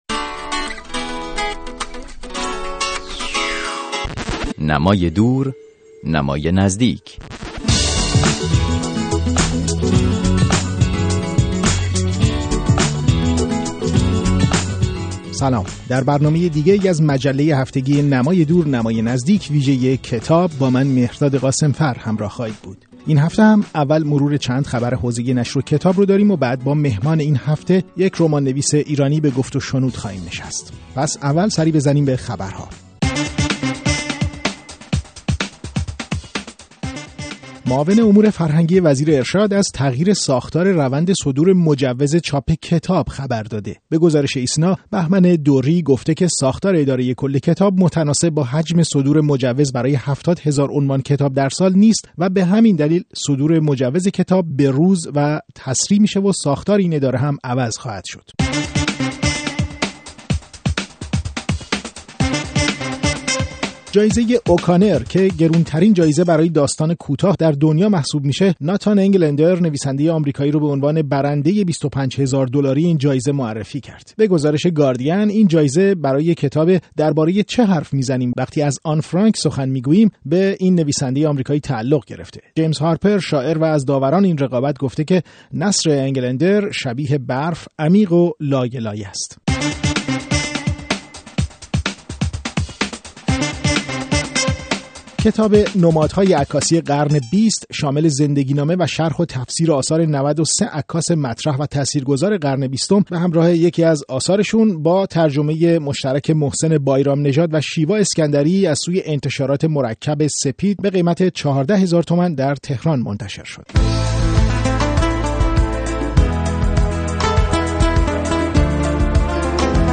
برنامه رادیویی